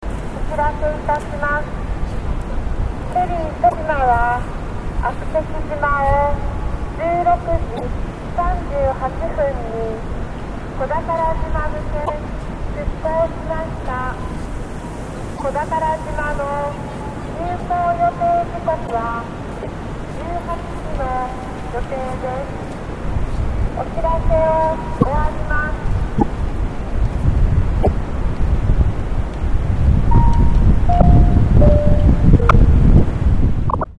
島では、各所に設置されたスピーカーで、「としま」の運行状況が、逐一放送される。